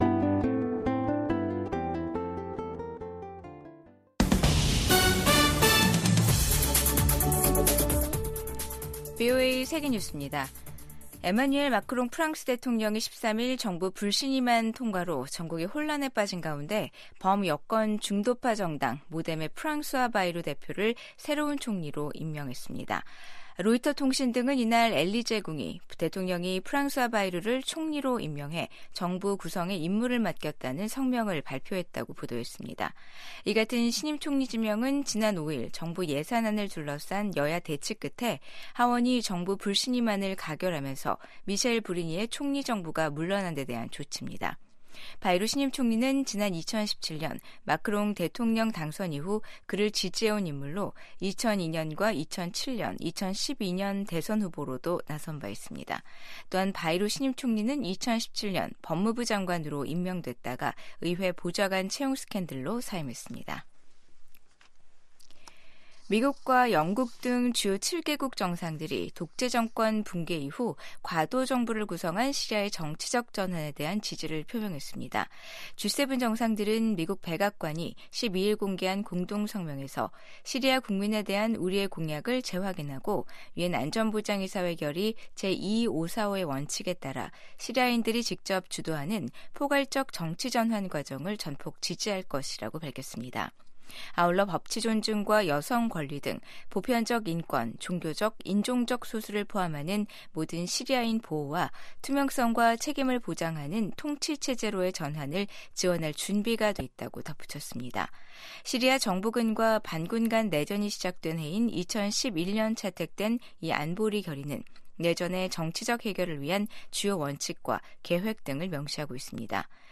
VOA 한국어 아침 뉴스 프로그램 '워싱턴 뉴스 광장'입니다. 비상계엄 사태를 일으킨 윤석열 한국 대통령에 대한 탄핵소추안 2차 투표가 내일 진행됩니다. 미국의 전직 고위 관리들은 북한이 연일 한국 대통령의 비상 계엄 선포와 탄핵 정국을 보도하는 것은 한국 정부를 비난하고 미한 동맹을 약화시키려는 선전선동 목적이 크다고 진단했습니다.